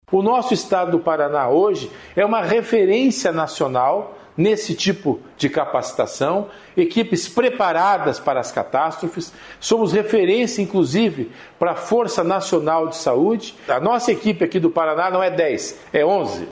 O objetivo do treinamento é qualificar e unificar os protocolos de atendimentos a acidentes com múltiplas vítimas, incluindo desde a comunicação entre as entidades até a gestão do atendimento no local do acidente, como explica o secretário de Estado da Saúde, César Neves.